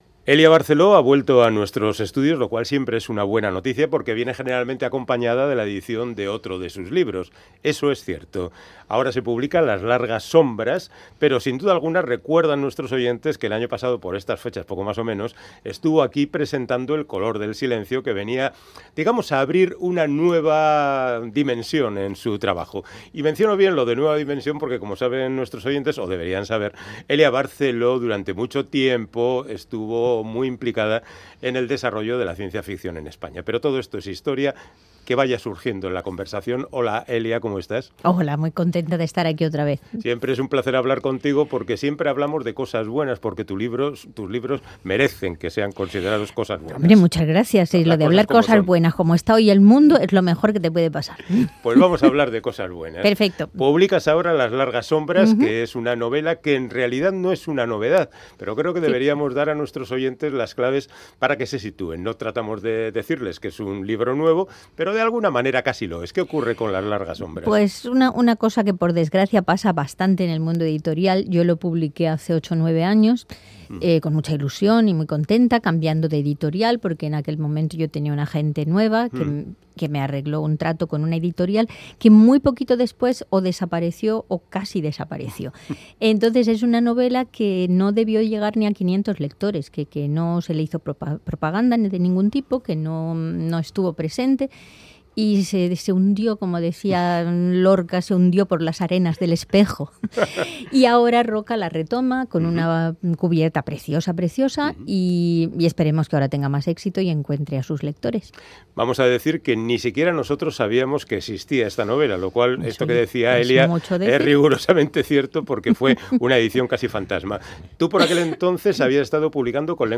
Audio: Charlamos con la escritora y profesora valenciana Elia Barceló, pionera del fantástico y la ciencia-ficción en España, que recupera una novela realista y generacional, Las largas sombras